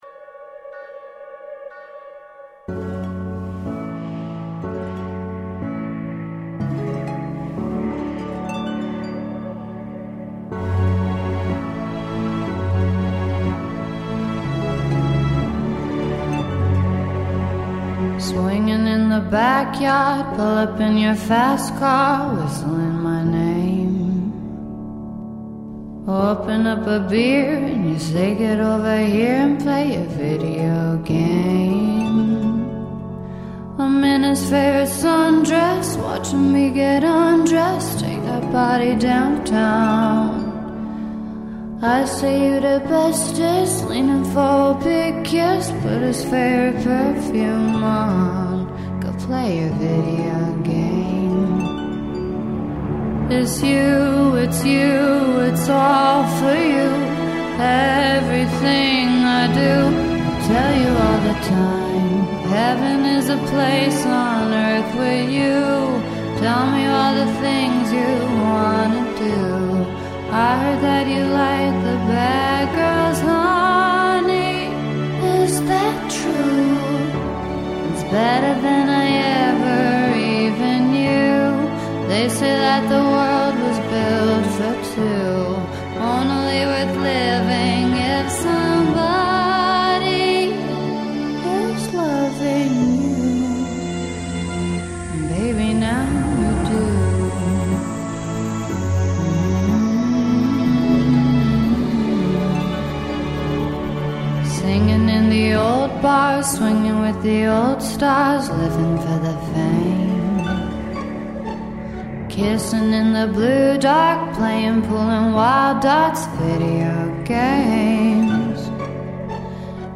Brooklyn singer
The song is a melancholy, achy, sultry, vintage…